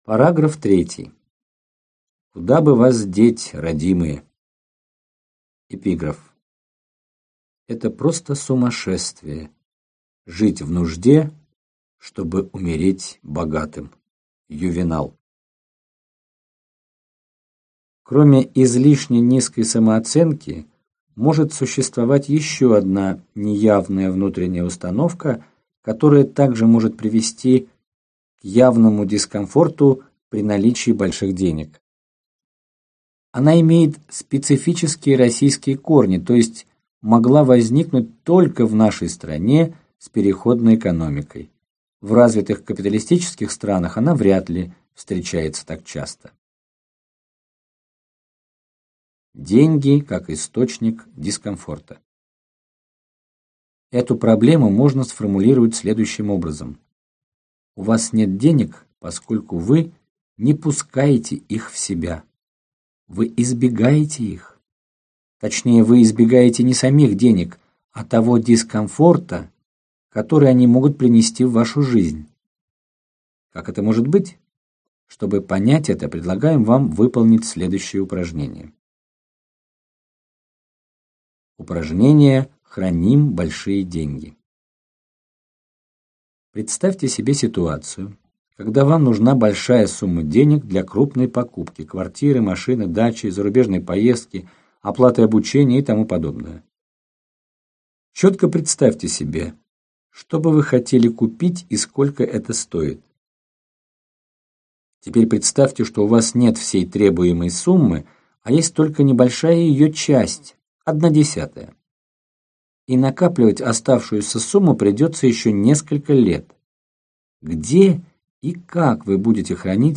Аудиокнига Что вам мешает быть богатым | Библиотека аудиокниг